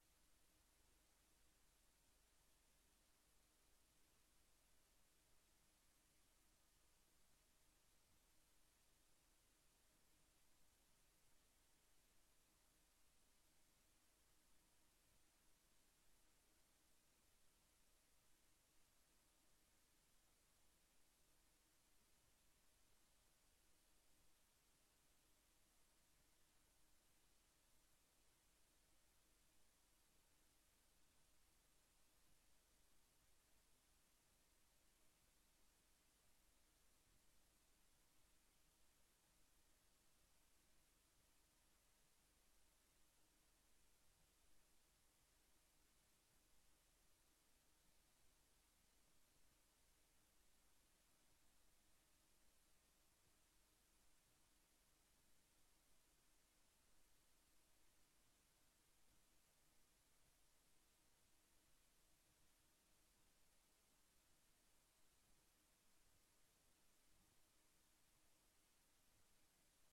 BOB-avond beeld- en oordeelsvormende sessies 16 januari 2025 19:30:00, Gemeente Leusden